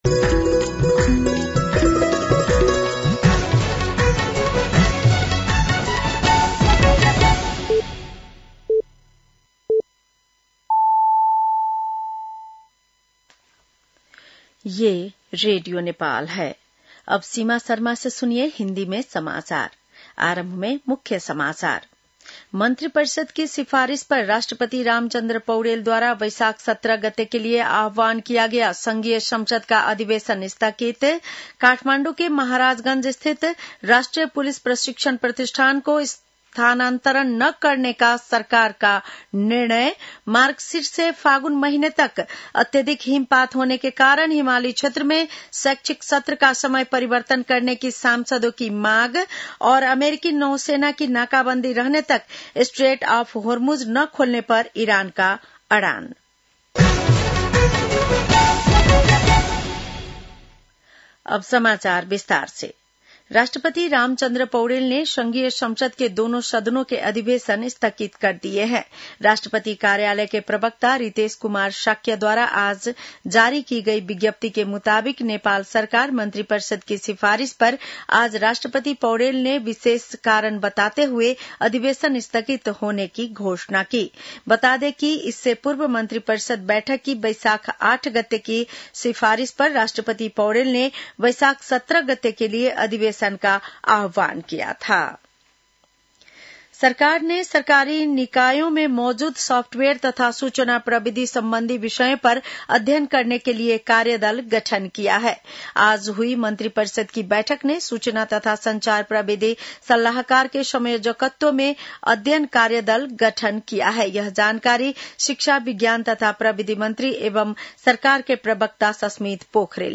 बेलुकी १० बजेको हिन्दी समाचार : १० वैशाख , २०८३